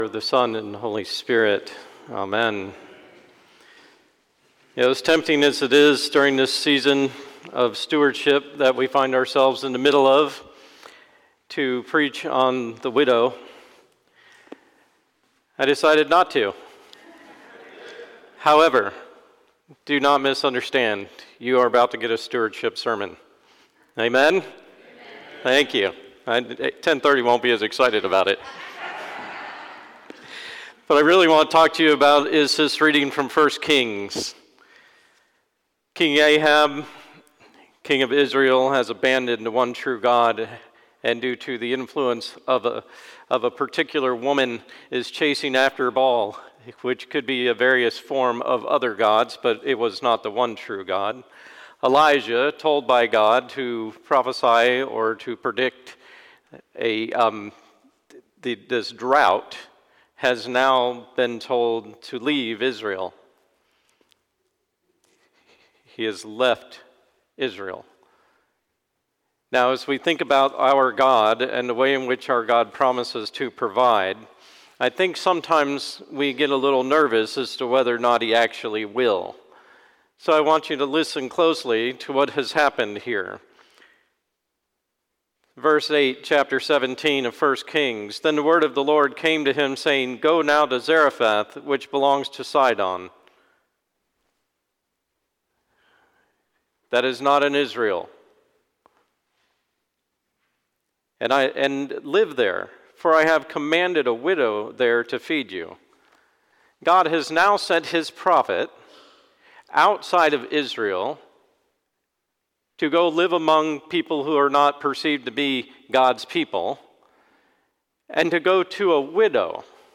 Sermon 11/10/24 Twenty-Fifth Sunday after Pentecost - Holy Innocents' Episcopal Church